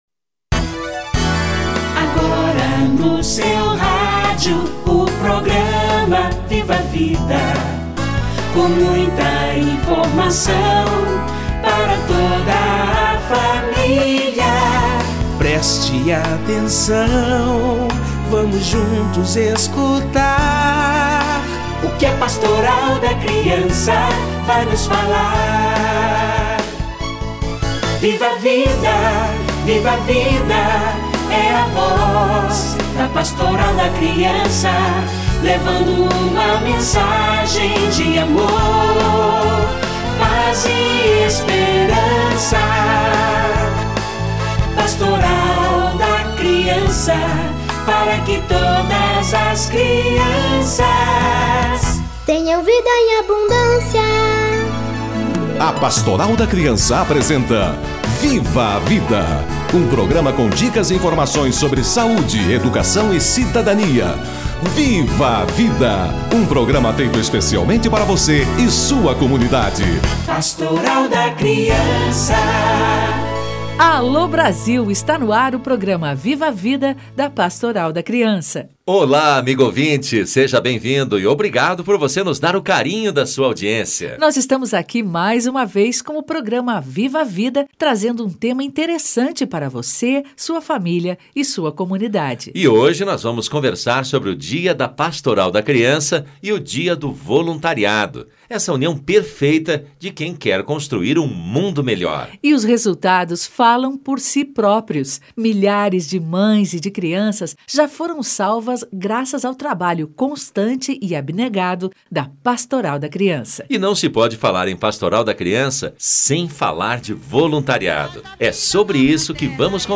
Dia da Pastoral da Criança / Dia Internacional do Voluntário - Entrevista